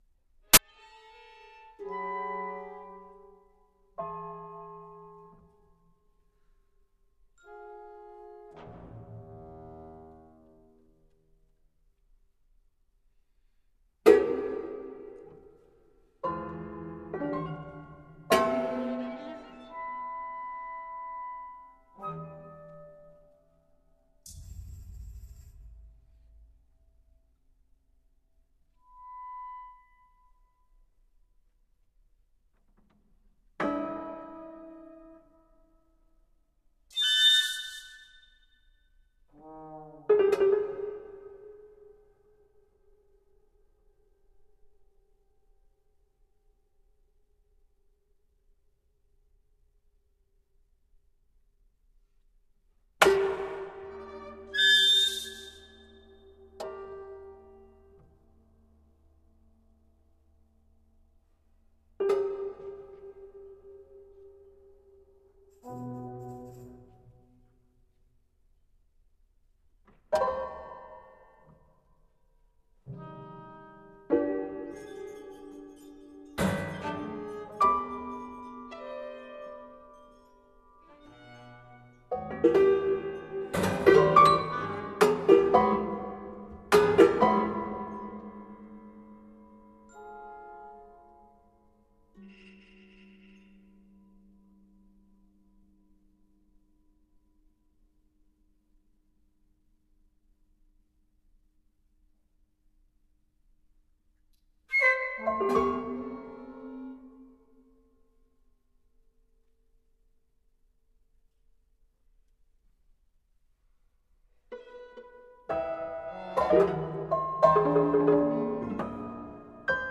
Cage, dal Concerto for prepared piano and chamber orch. - III.mp3 — Laurea Triennale in Scienze e tecnologie della comunicazione